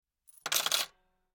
Money,Coins,Drop In Cash Register.ogg